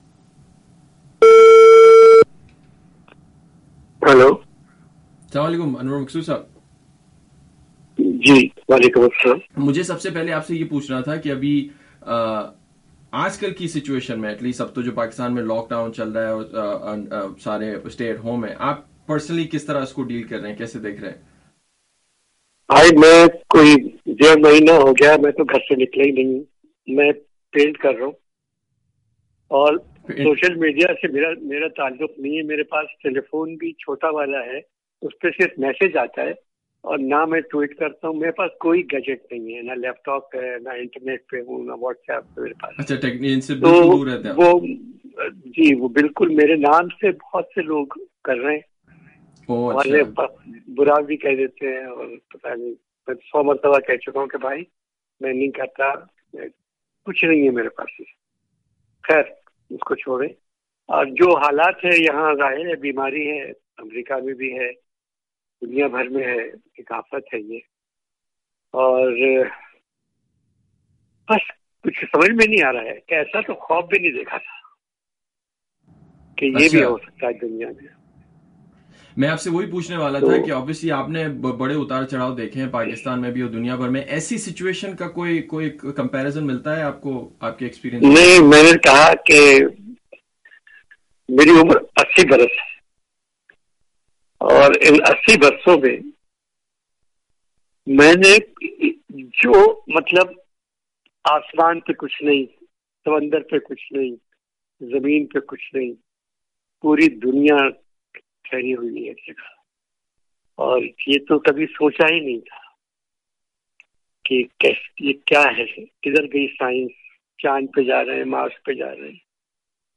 تفصیلی گفتگو۔